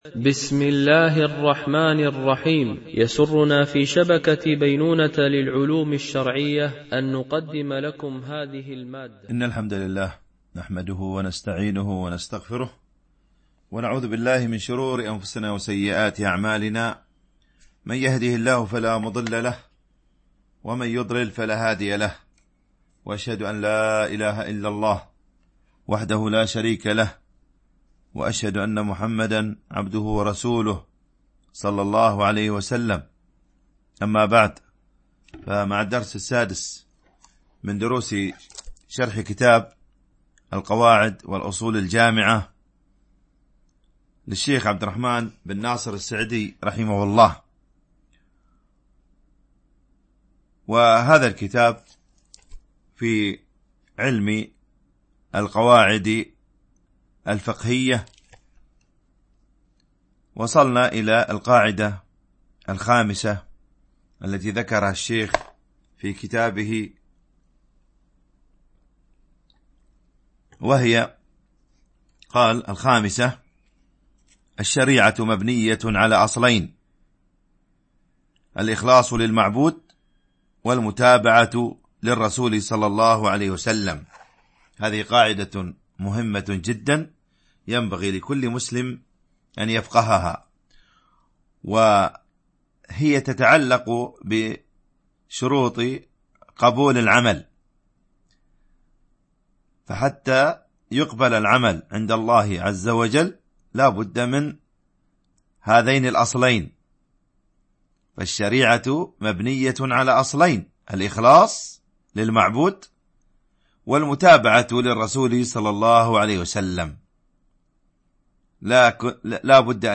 شرح القواعد والأصول الجامعة والفروق والتقاسيم البديعة النافعة - الدرس 6 ( الشريعة مبنية على الاخلاص والمتابعة )